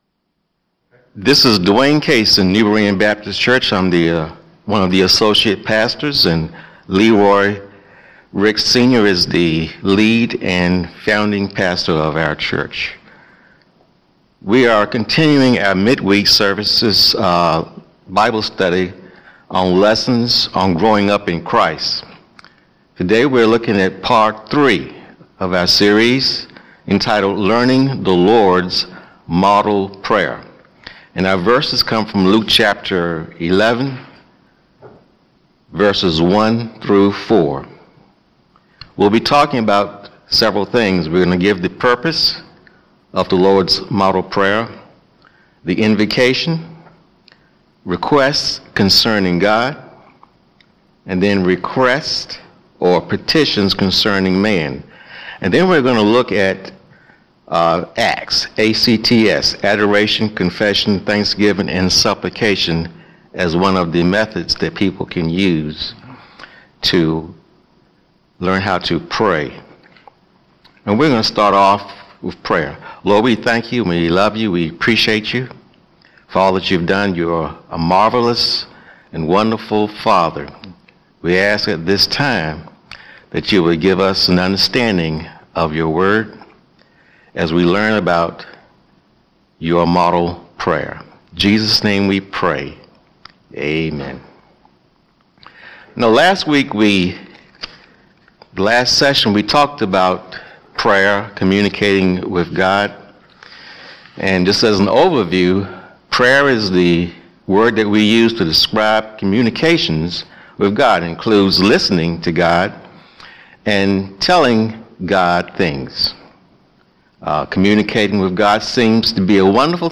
Mid-Week Bible Study Lessons